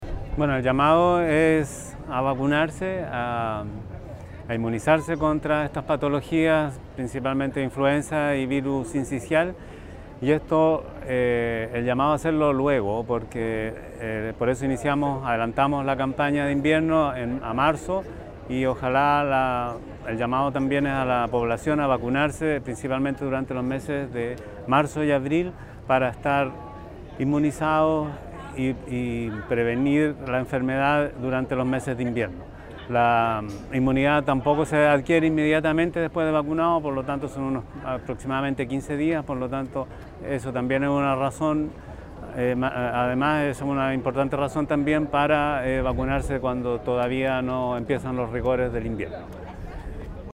Por su parte, el Seremi (s) de Salud, Tomás Balaguer, recalcó la importancia de vacunarse cuando antes.
CUNA-SEREMI-S-DE-SALUD_VACUNACION.mp3